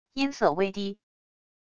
音色微低wav音频